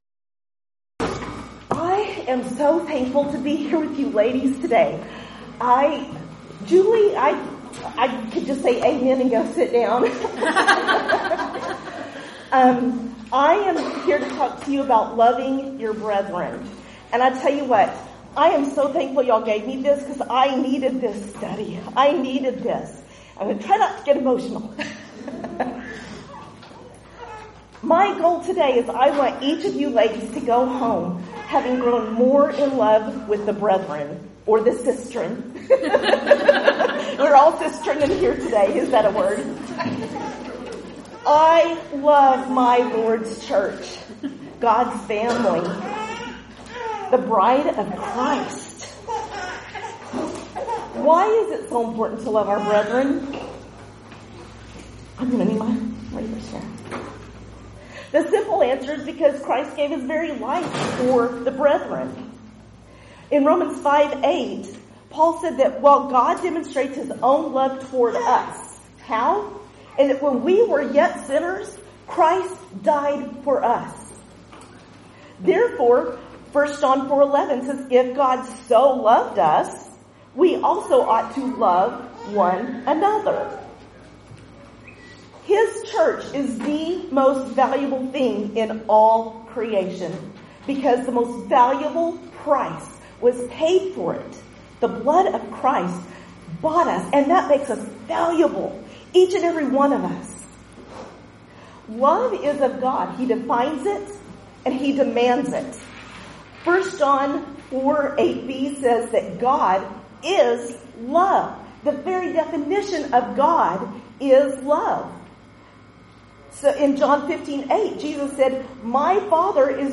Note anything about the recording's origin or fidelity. Event: 8th Annual Women of Valor Ladies Retreat